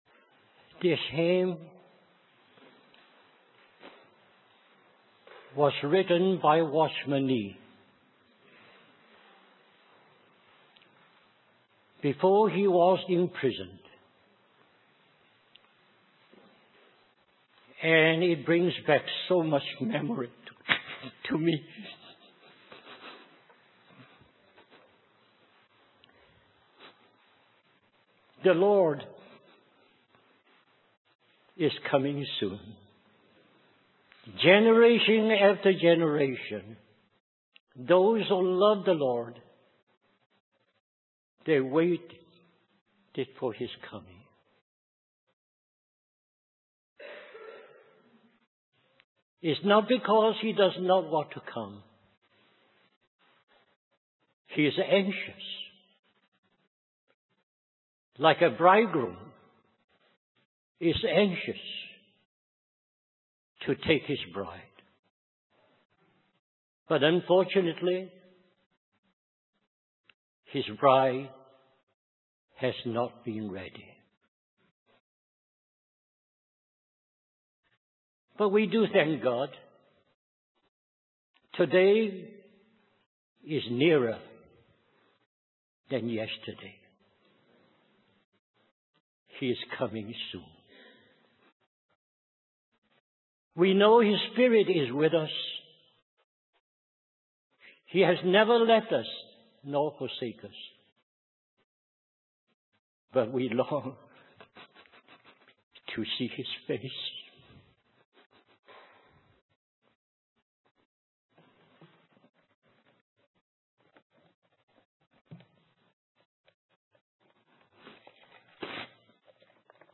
In this sermon, the preacher emphasizes the importance of being faithful and responsible with the gifts that God has given us. He uses the parable of the talents to illustrate this point, highlighting how the servants who traded and multiplied their talents were praised, while the one who buried his talent was rebuked.